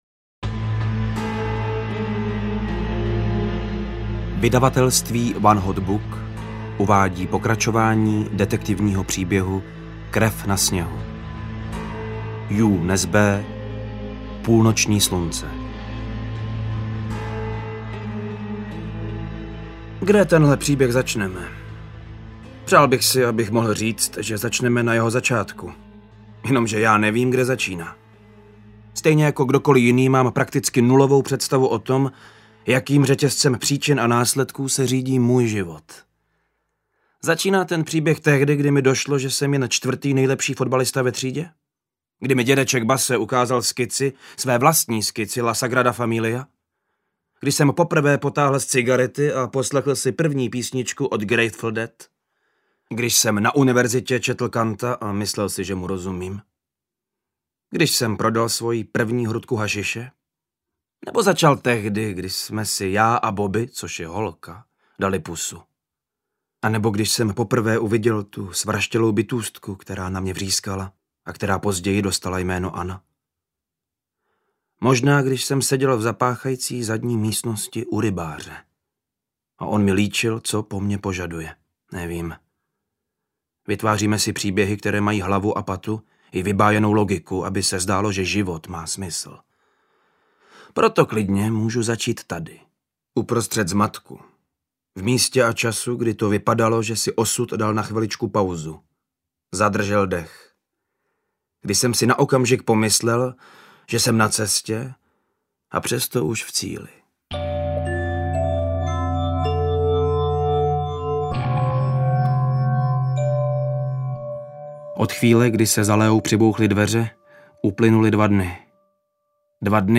Ukázka z knihy
pulnocni-slunce-krev-na-snehu-ii-audiokniha